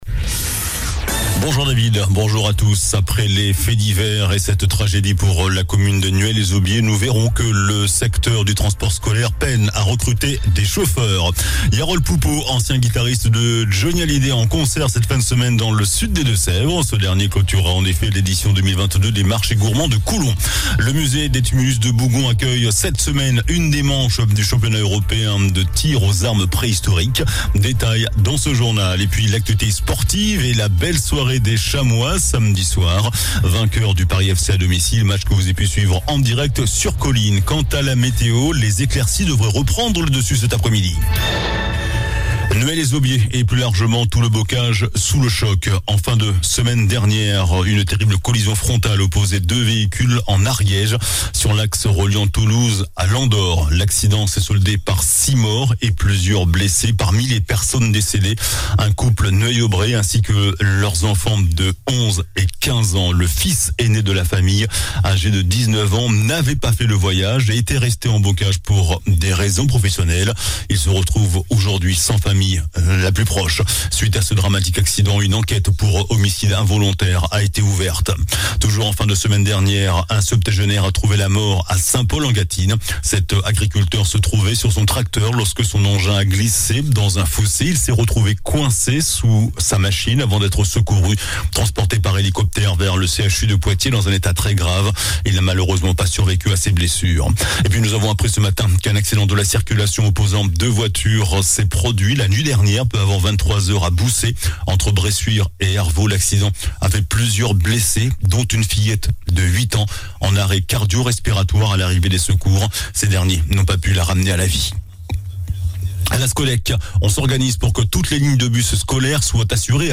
JOURNAL DU LUNDI 22 AOÛT